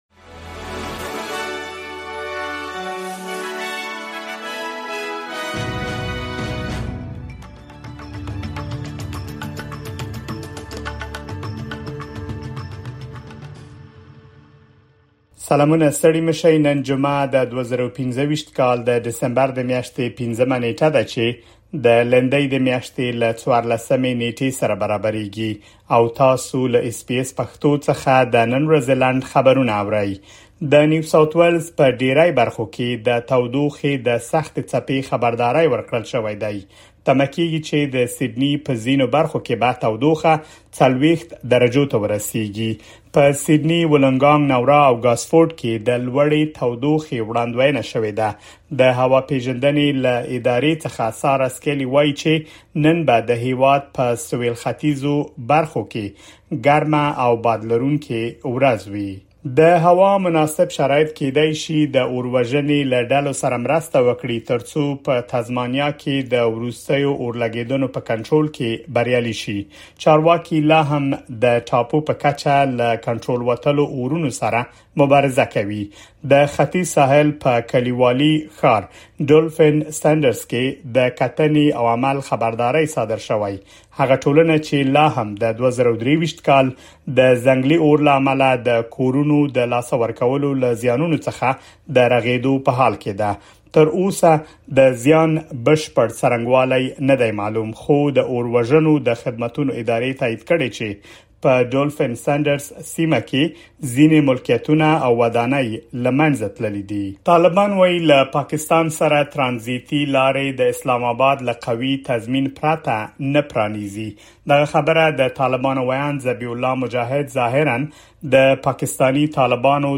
د اس بي اس پښتو د نن ورځې لنډ خبرونه |۵ ډسمبر ۲۰۲۵